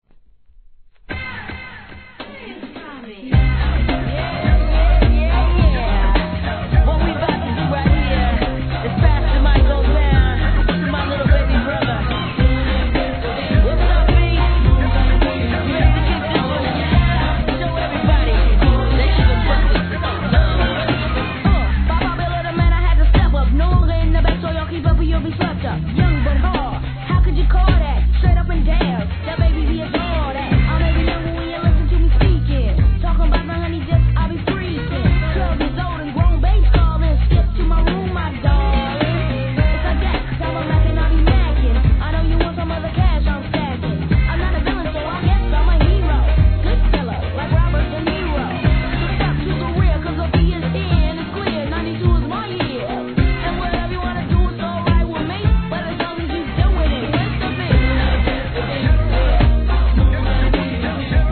HIP HOP/R&B
イントロから洒落たPIANOのJAZZYなトラックで踊れます♪ No. タイトル アーティスト 試聴 1.